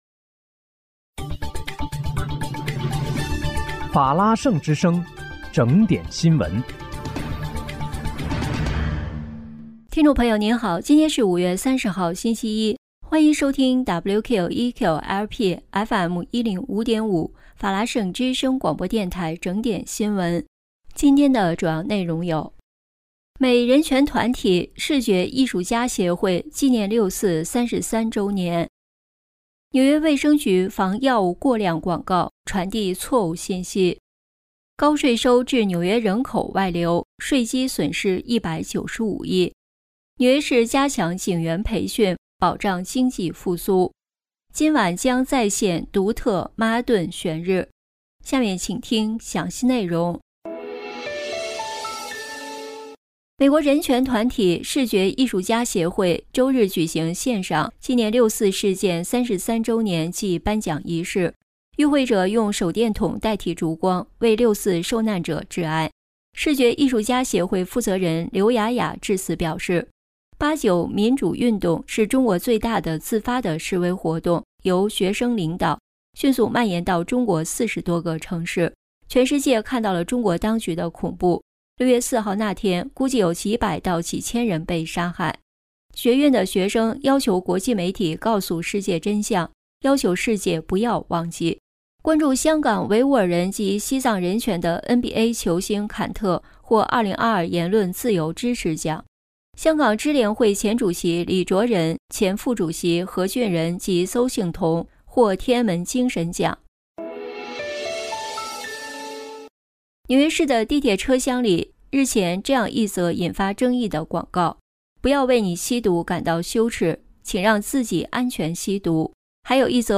今天是5月30号，星期一，欢迎收听WQEQ-LP FM105.5法拉盛之声广播电台整点新闻。